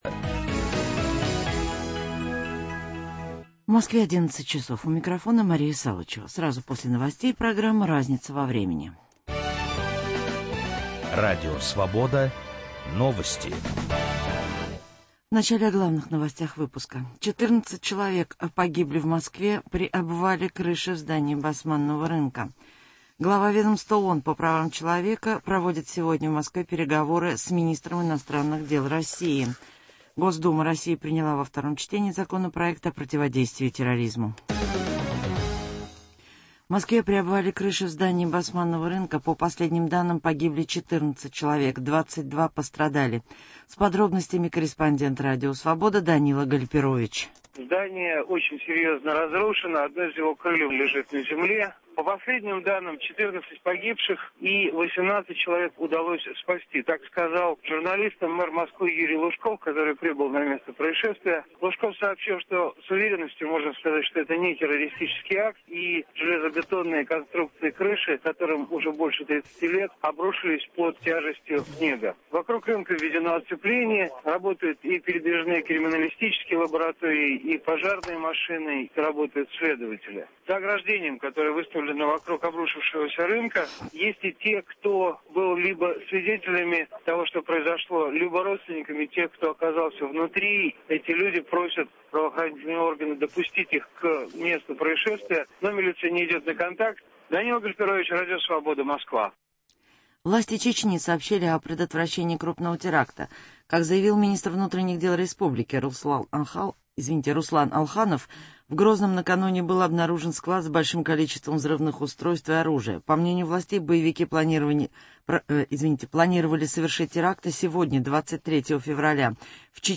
Утром в газете, а с 11 до полудня - обсуждение в прямом эфире самых заметных публикации российской и зарубежной печати. Их авторы и герои - вместе со слушателями.